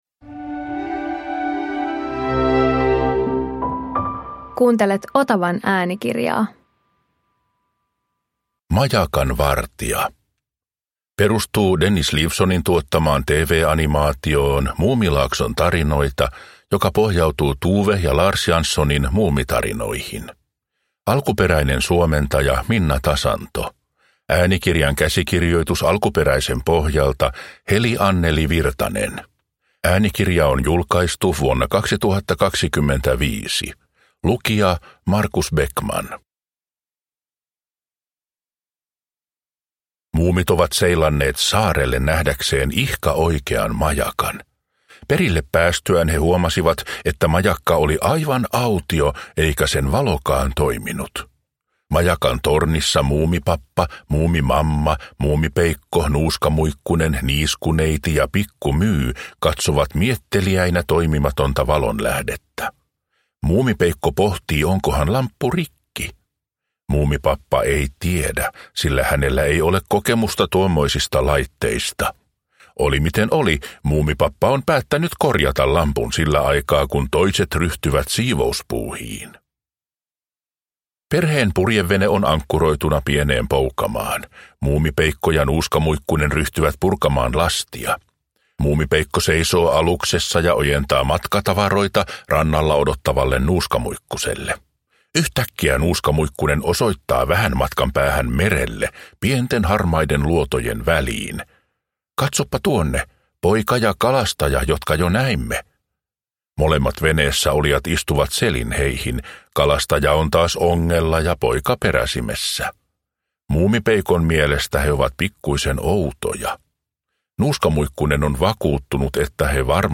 Muumi - Majakan vartija – Ljudbok